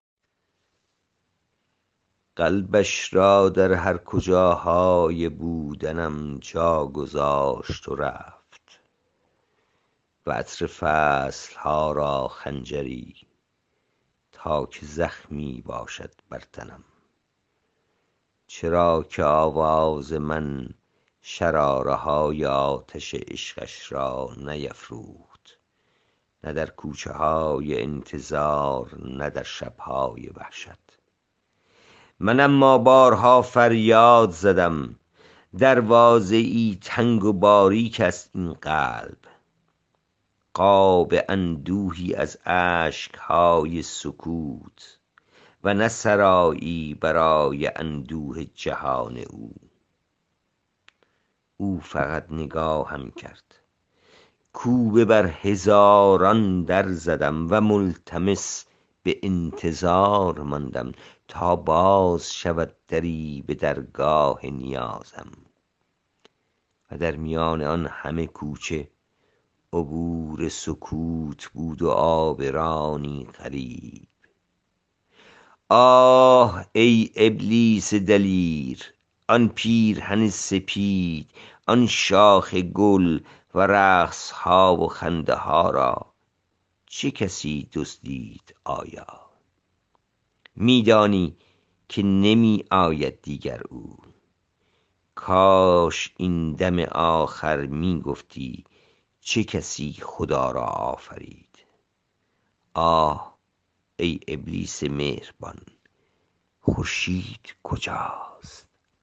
این شعر را با صدای شاعر از این جا بشنوید٬